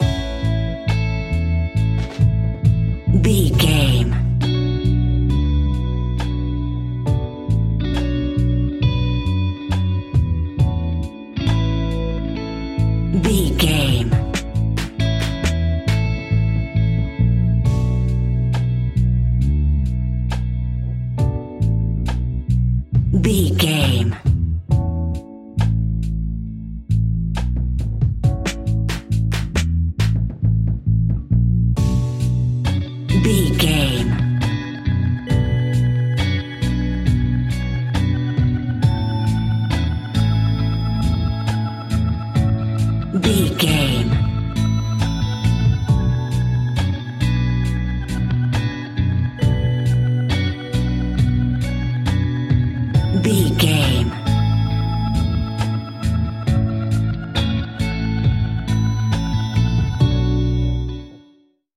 Ionian/Major
A♭
chilled
laid back
Lounge
sparse
new age
chilled electronica
ambient
atmospheric
instrumentals